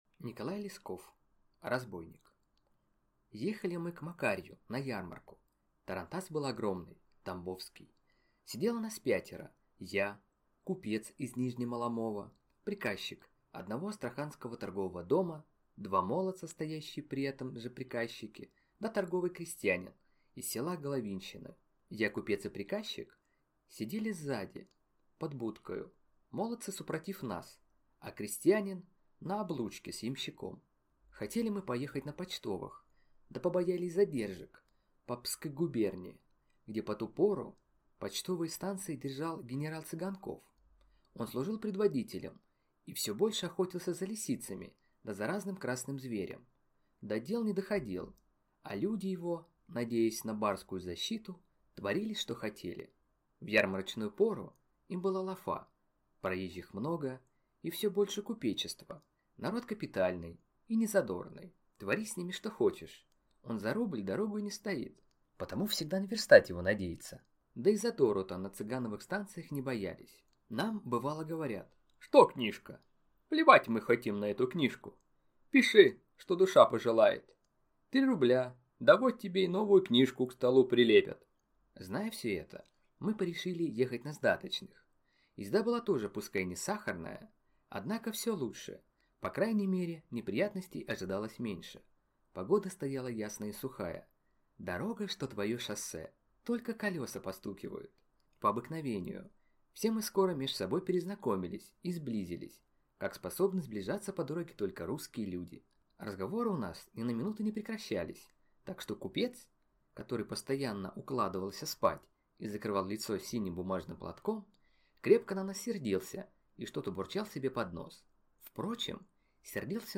Аудиокнига Разбойник | Библиотека аудиокниг